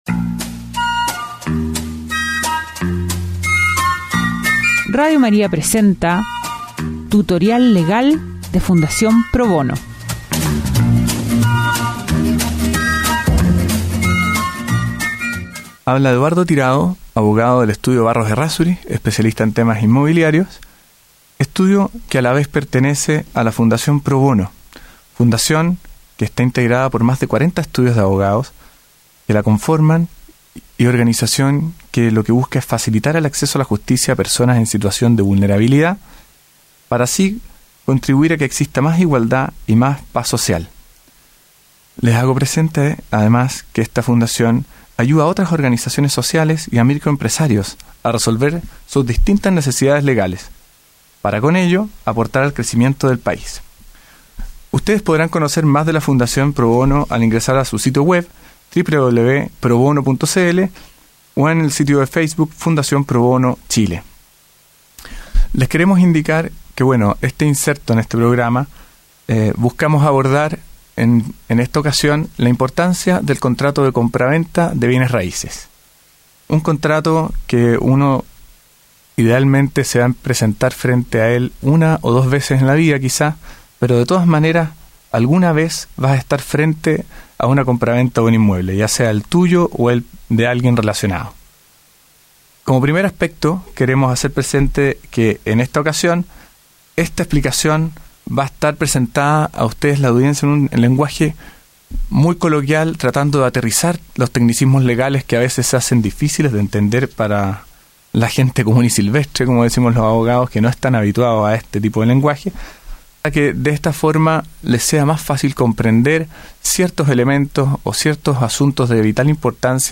Fuente: Radio María. Cápsula informativa